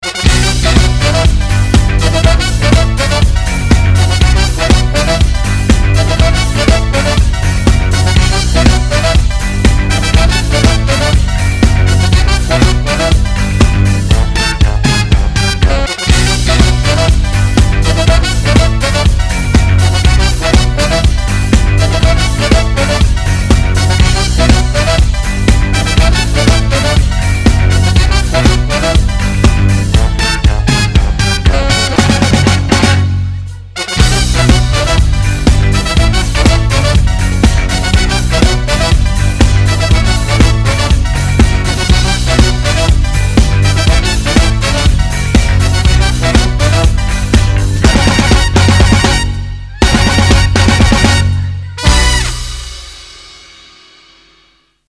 • Качество: 256, Stereo
без слов
инструментальные
труба